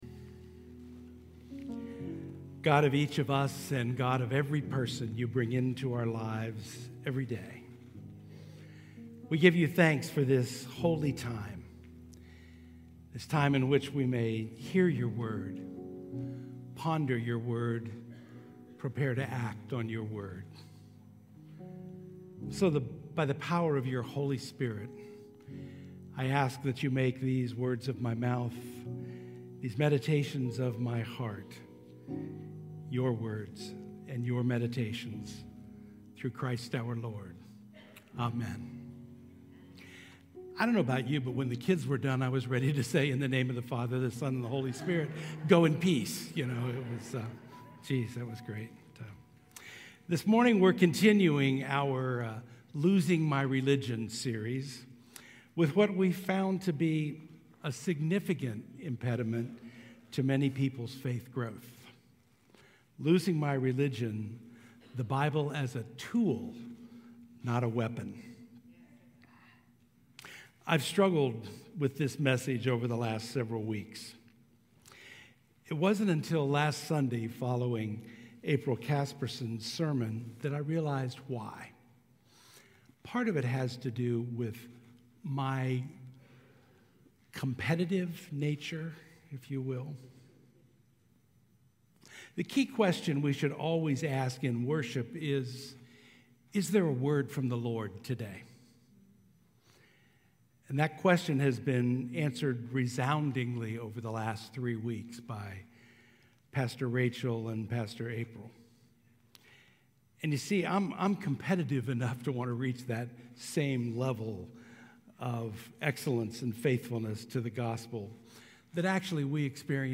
9:30 AM Redemption Worship Service 02/23/25